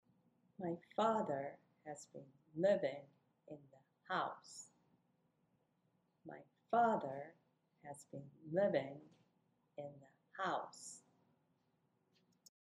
次はもう少し大げさに
内容語にストレスを置いて